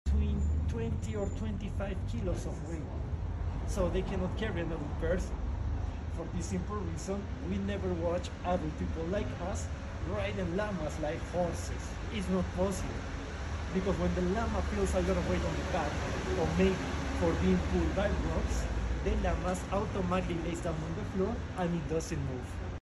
Bloody Bueno Peru free walking tour Cusco